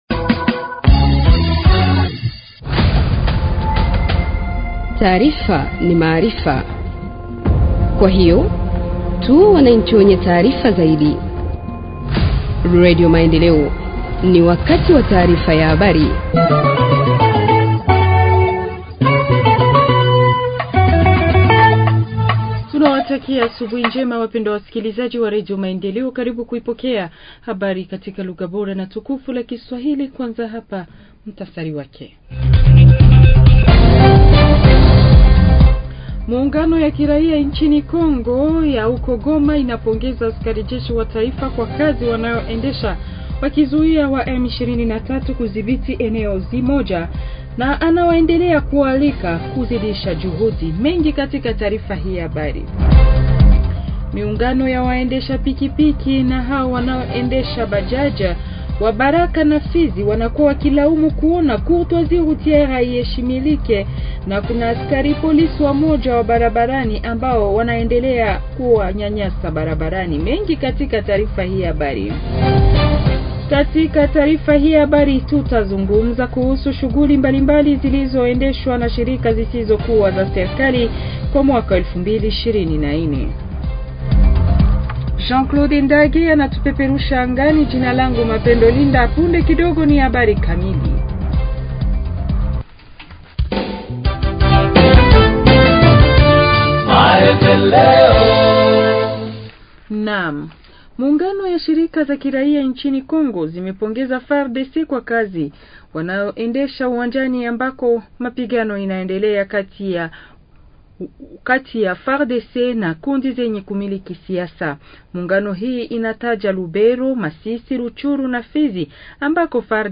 Journal swahili du 02 janvier 2025 – Radio Maendeleo